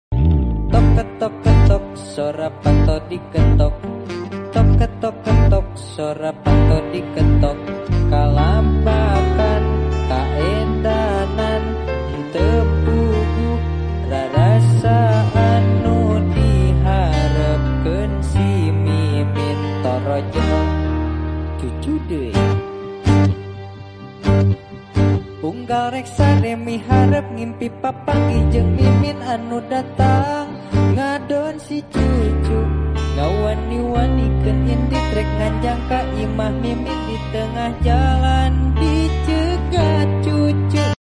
LIVE ACOUSTIC COVER
Gitar
Gitar Bass
Keyboard